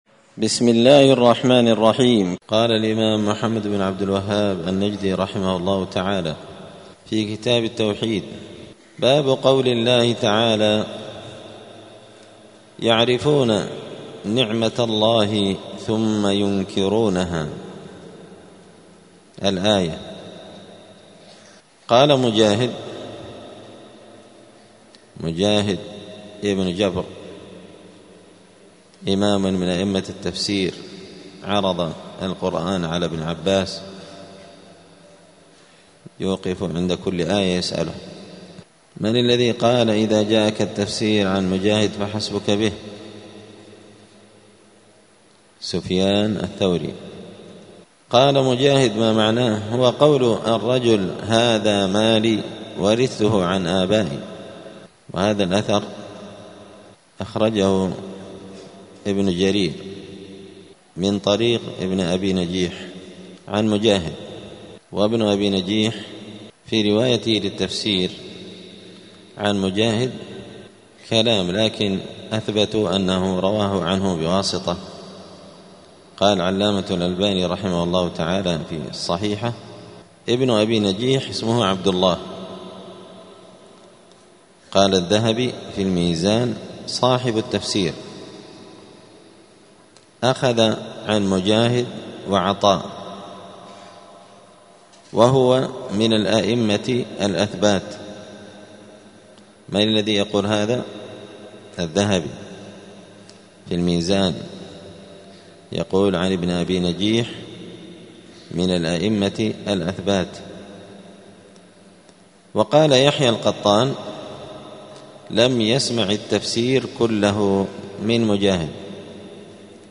دار الحديث السلفية بمسجد الفرقان قشن المهرة اليمن
*الدرس الثامن عشر بعد المائة (118) باب قول الله تعالى {يعرفون نعمت الله ثم ينكرونها وأكثرهم الكافرون}*